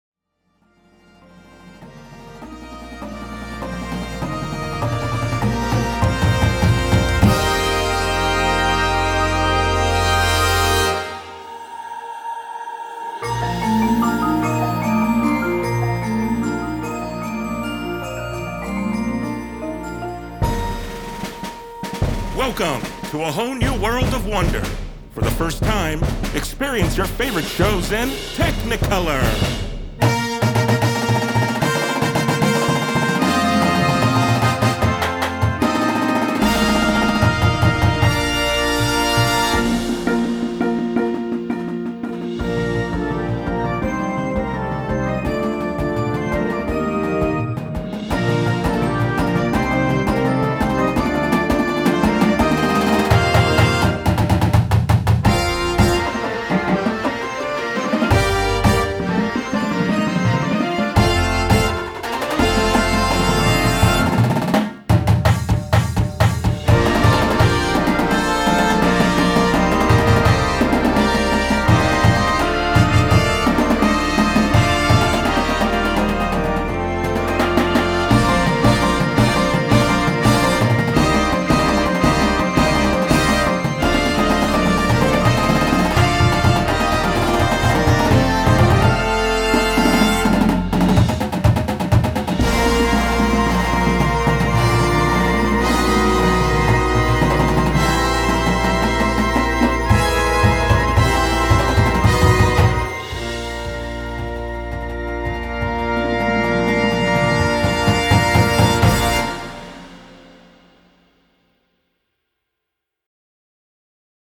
Marching Band Shows
and the swing of a big band sound